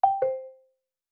spoken_feedback_disabled.wav